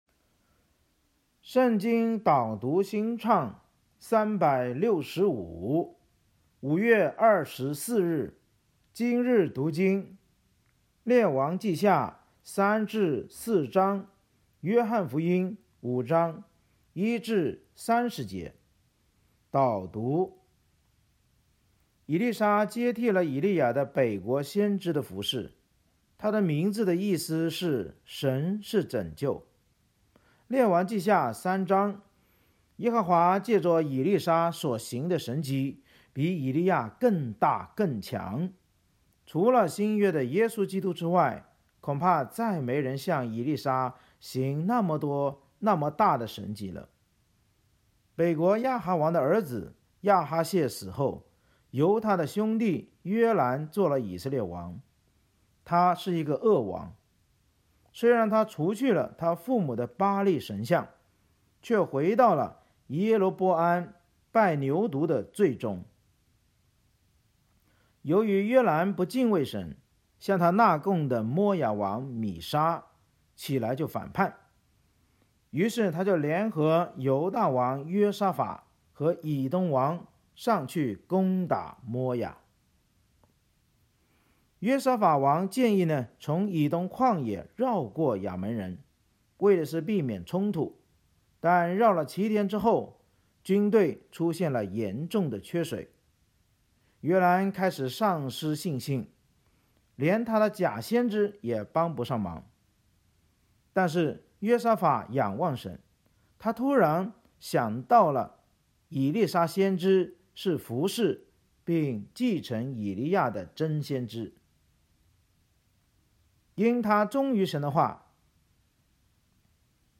【经文朗读】（中文）